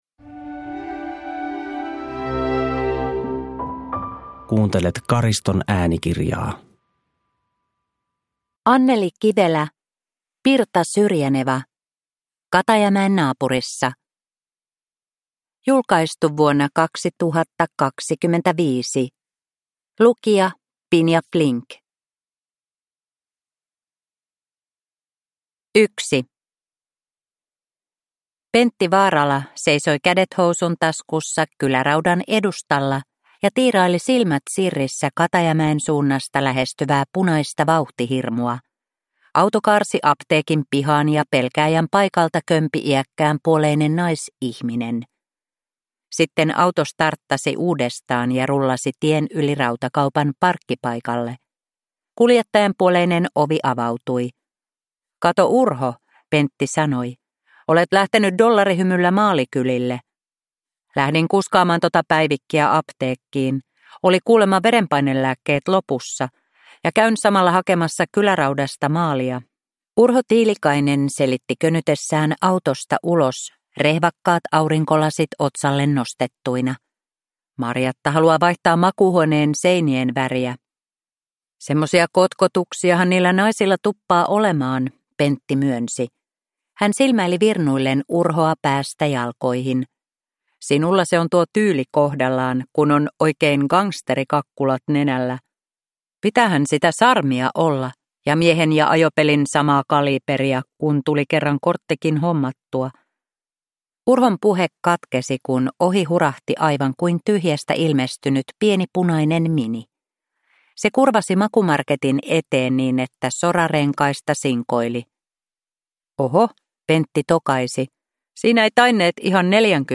Katajamäen naapurissa (ljudbok) av Anneli Kivelä